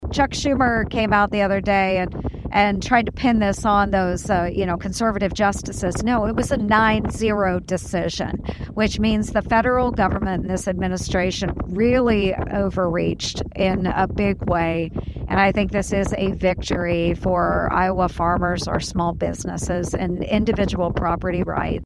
Ernst made her comments Friday in Willey.